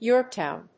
/ˈjɔˌrktaʊn(米国英語), ˈjɔ:ˌrktaʊn(英国英語)/